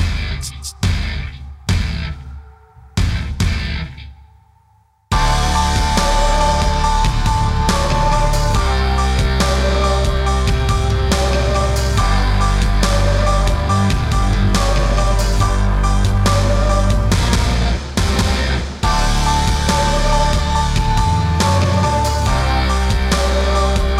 Clean Pop (2010s) 4:00 Buy £1.50